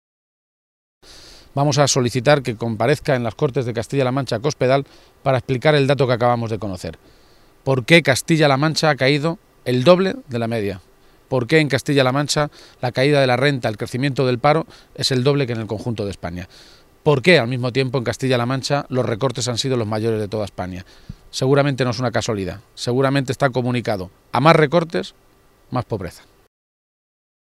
El dirigente socialista realizó estas declaraciones en el municipio albaceteño de Molinicos, hasta donde se desplazó para mantener encuentros con miembros de la Plataforma de Afectados por el Cierre del Punto de Atención de Urgencias y con militantes de la comarca.
Cortes de audio de la rueda de prensa